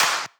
VEC3 Claps 056.wav